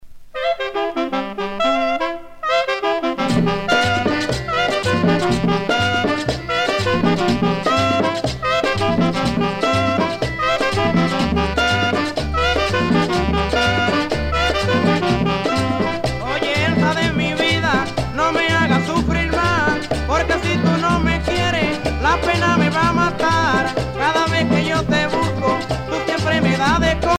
danse : merengue
Pièce musicale éditée